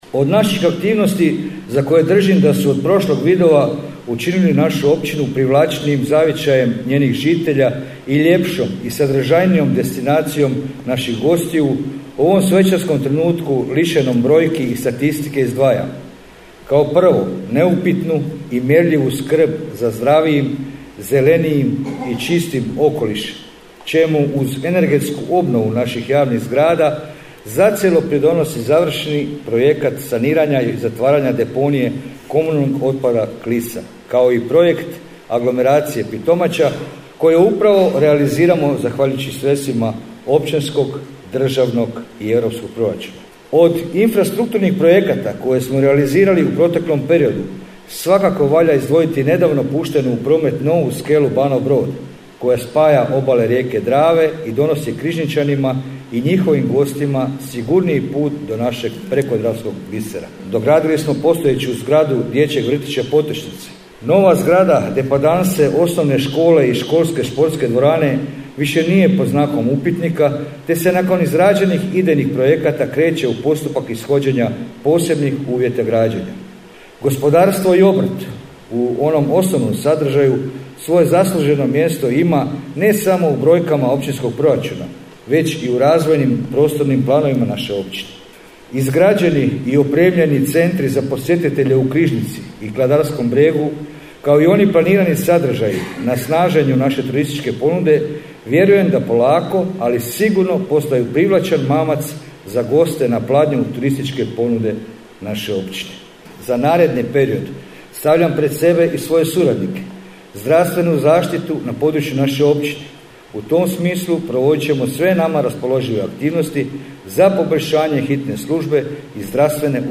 FOTO: Vidovo u Pitomači: Održana svečana sjednica Općinskog vijeća
Povodom obilježavanja Dana Općine Pitomača u Centru za kulturu “Drago Britvić” održana je svečana sjednica Općinskog vijeća Općine Pitomača.
Svečanu sjednicu je otvorio predsjednik Općinskog vijeća Općine Pitomača Rikard Bakan, a zatim je riječ preuzeo općinski načelnik Općine Pitomača Željko Grgačić: